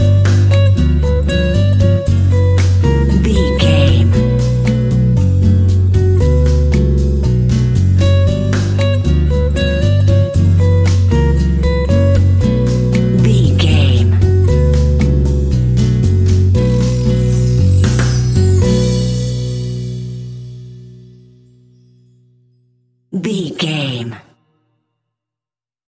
Aeolian/Minor
romantic
maracas
percussion spanish guitar